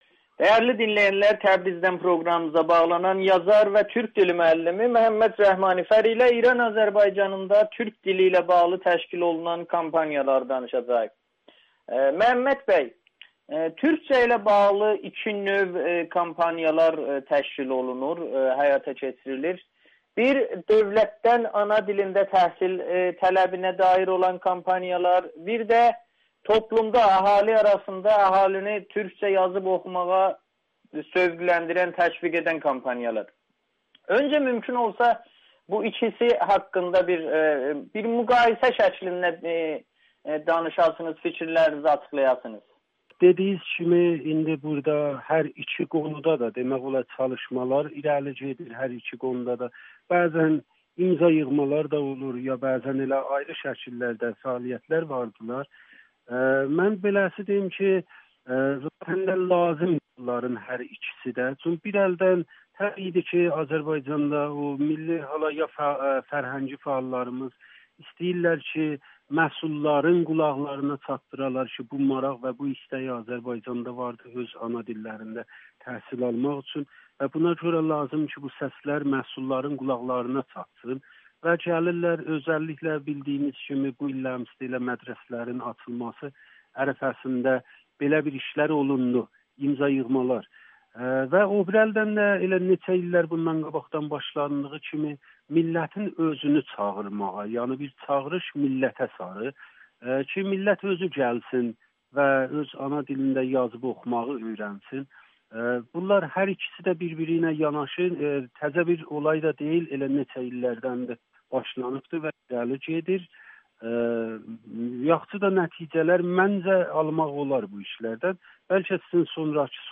Audio-Müsahibə